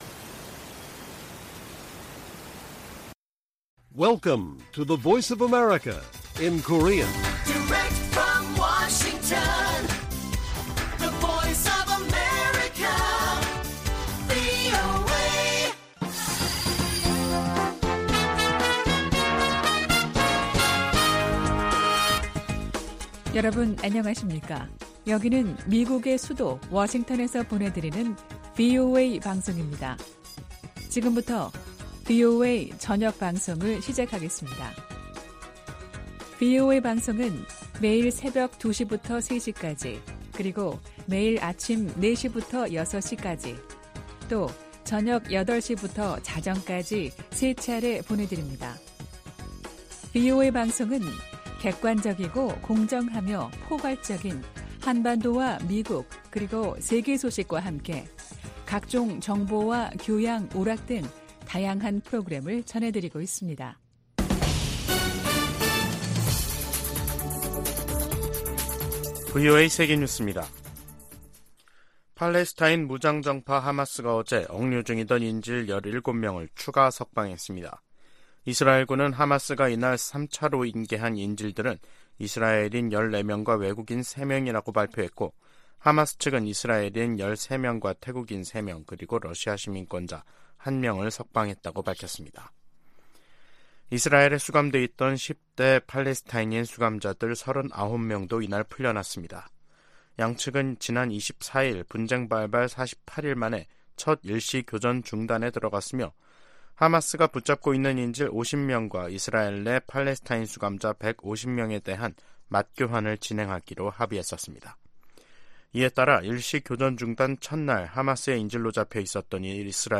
VOA 한국어 간판 뉴스 프로그램 '뉴스 투데이', 2023년 11월 27일 1부 방송입니다.